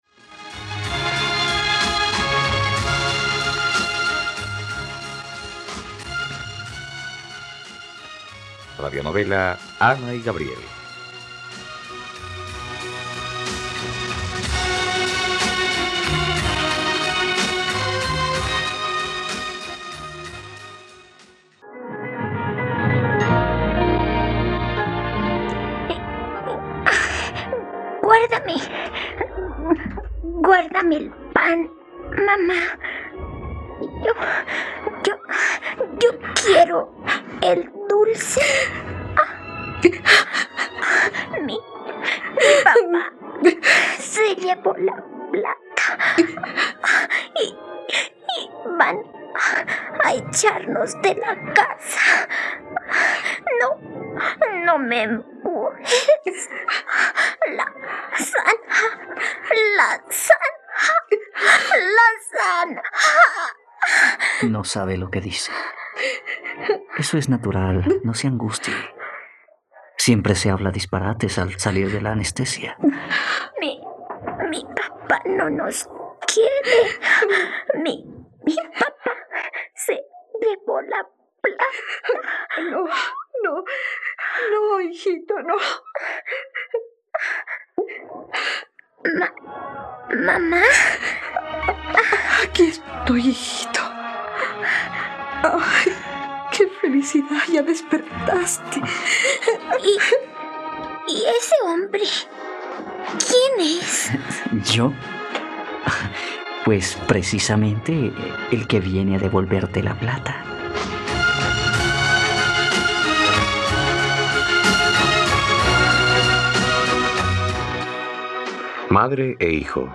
..Radionovela. Escucha ahora el noveno capítulo de la historia de amor de Ana y Gabriel en la plataforma de streaming de los colombianos: RTVCPlay.